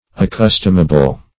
Search Result for " accustomable" : The Collaborative International Dictionary of English v.0.48: Accustomable \Ac*cus"tom*a*ble\, a. Habitual; customary; wonted.